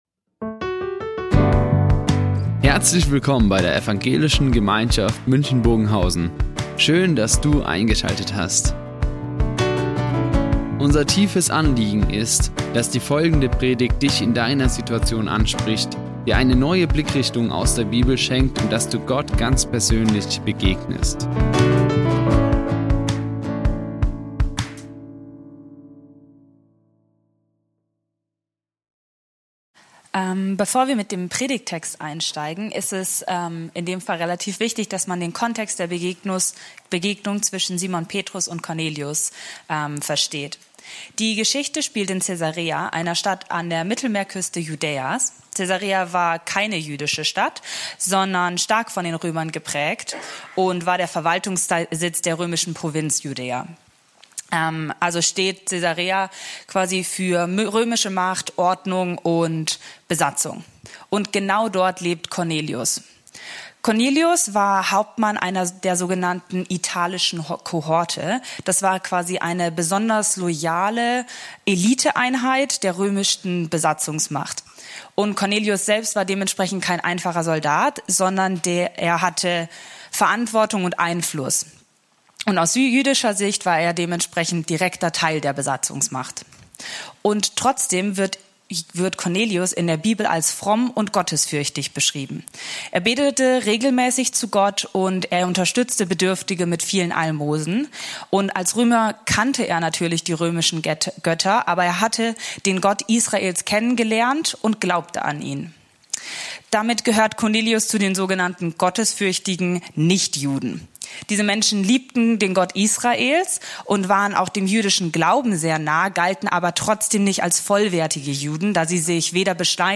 Nicht besser, sondern neu werden | Predigt Apostelgeschichte 10, 25-36 ~ Ev.
sondern neu werden" Die Aufzeichnung erfolgte im Rahmen eines Livestreams.